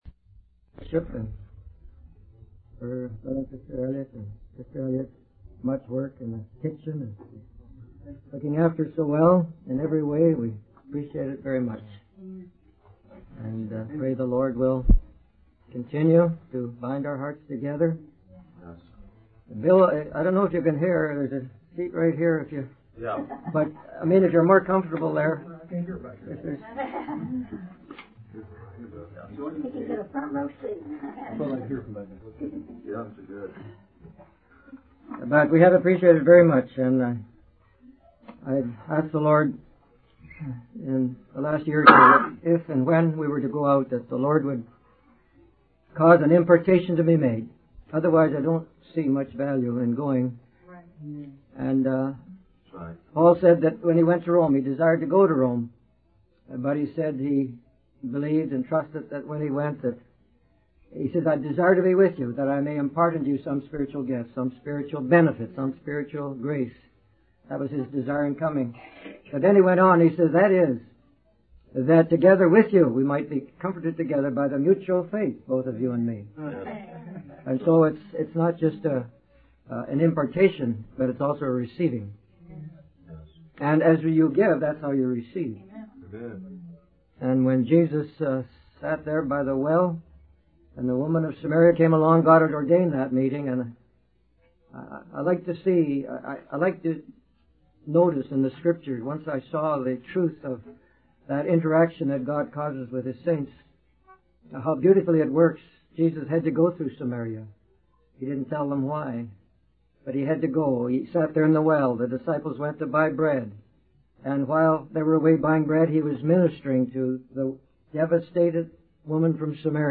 In this sermon, the preacher emphasizes the need for people to respond to God's challenges and to trust in His provision. He uses the story of Andrew mentioning the presence of a young boy with five barley loaves and two fish to illustrate how God can work miracles with even the smallest offerings. The preacher also references the story of the potter and the clay to highlight how God can reshape and mold His people according to His will.